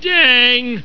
Grandpa Simpson saying "Dang"